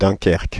dæ̃kɛʁk.)
Fr-Dunkerque.ogg